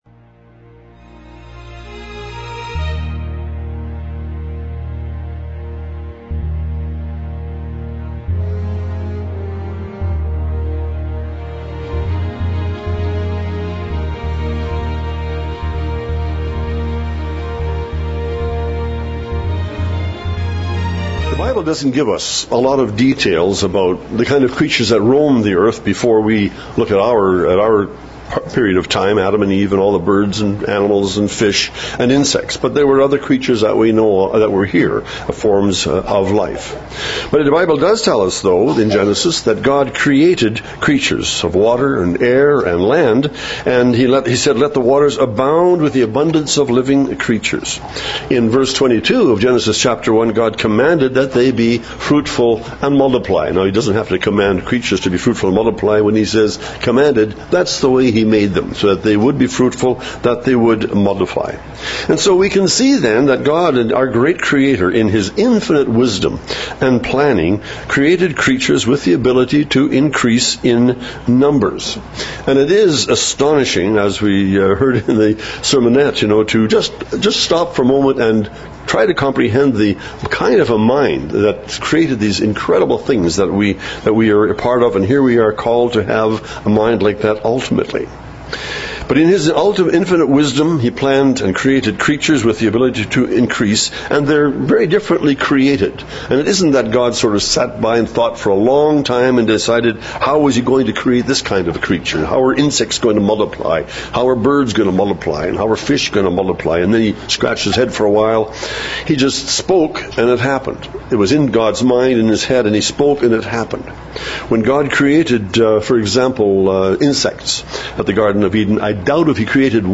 Sexual immorality is spoken of frequently in the Bible. This sermon examines what the Bible says.